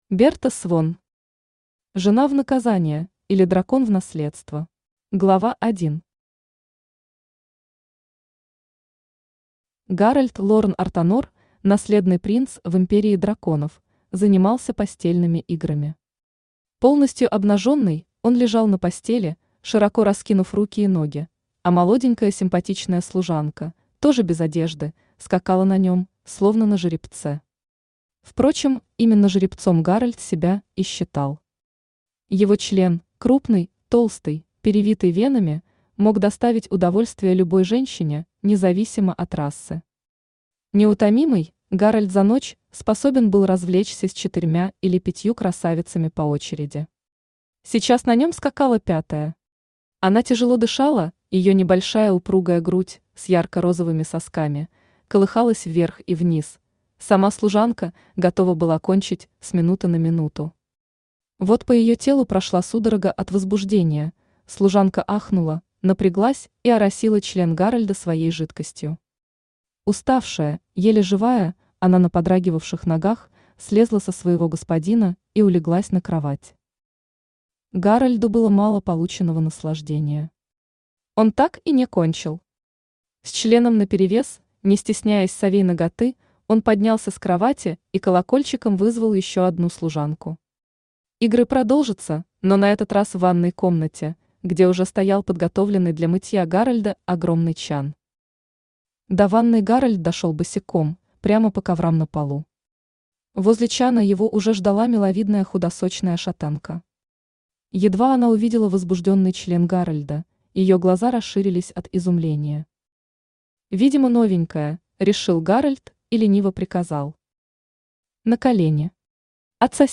Аудиокнига Жена в наказание, или Дракон в наследство | Библиотека аудиокниг
Aудиокнига Жена в наказание, или Дракон в наследство Автор Берта Свон Читает аудиокнигу Авточтец ЛитРес.